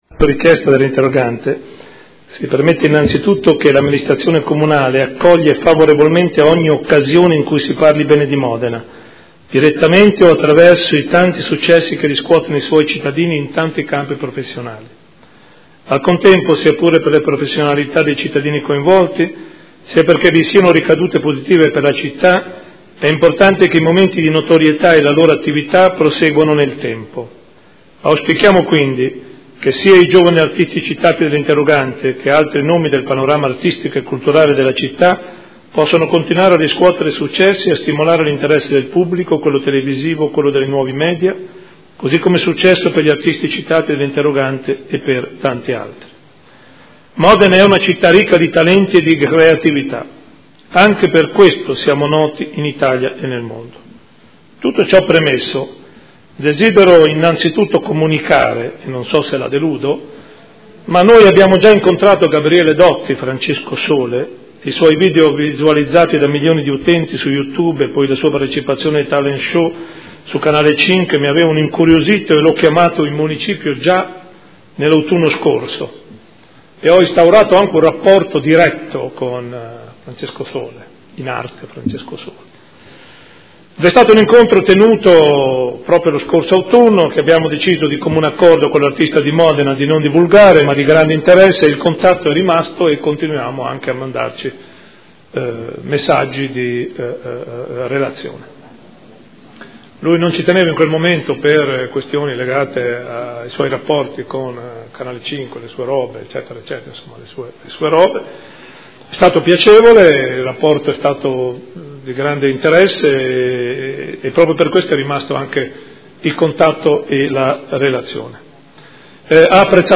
Sindaco — Sito Audio Consiglio Comunale
Sindaco